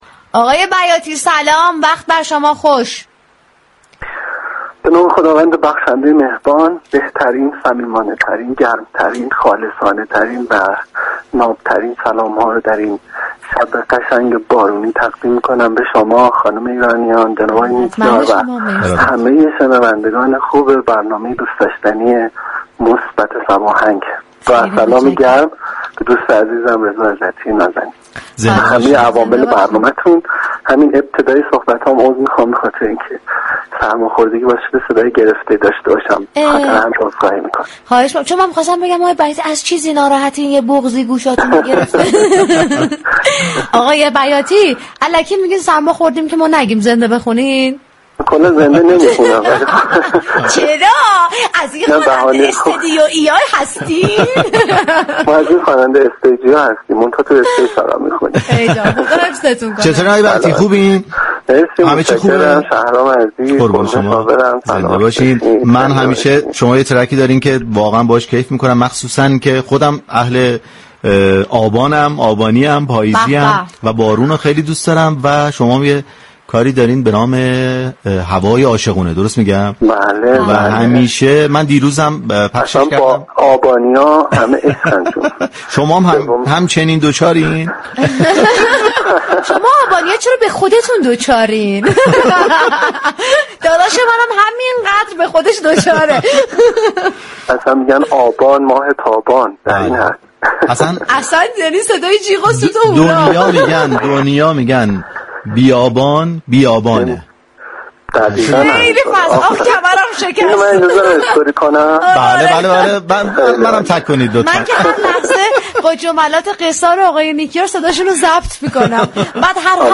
به گزارش روابط عمومی رادیو صبا ، "مثبت صباهنگ " جنگ عصرگاهی بر پایه موسیقی و گفتگوی صمیمی در رادیو صبا است، كه با محوریت پخش ترانه وموسیقی های شاد راهی آنتن صبا می شود .